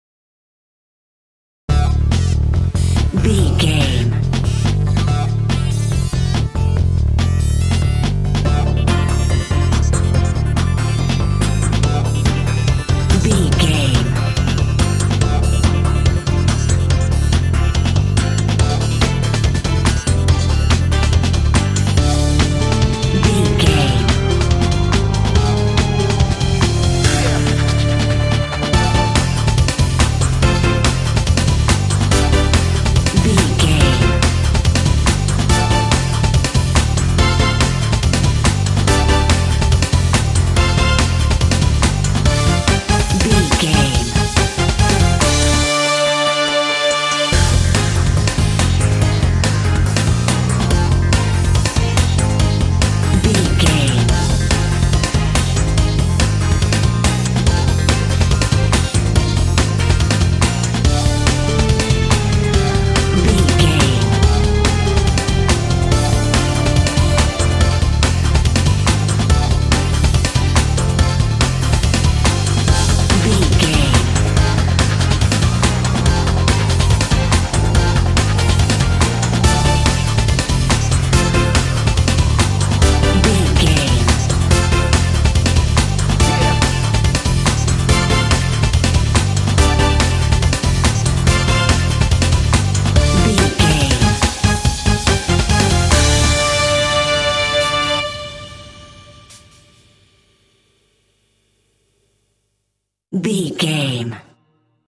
Ionian/Major
powerful
energetic
heavy
synthesiser
drum machine
horns
strings
electronica